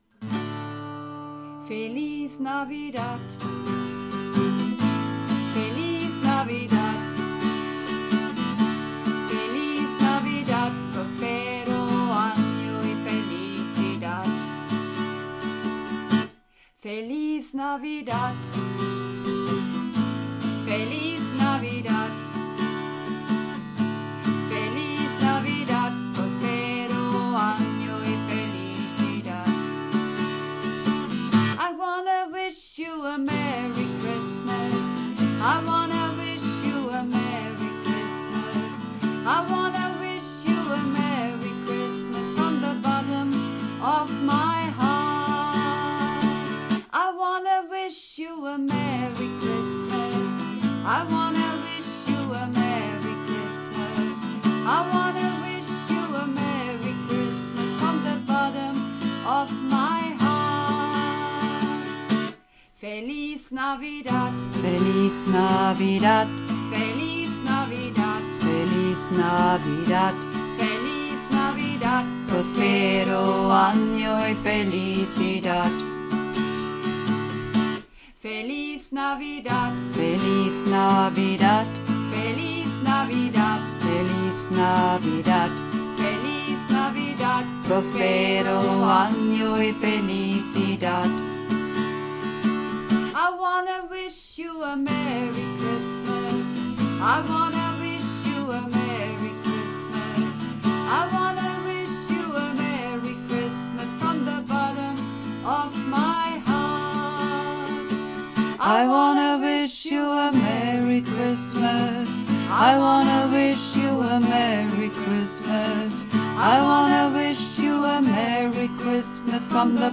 Übungsaufnahmen - Feliz Navidad
Runterladen (Mit rechter Maustaste anklicken, Menübefehl auswählen)   Feliz Navidad (Bass und Männer)
Feliz_Navidad__2_Bass_Maenner.mp3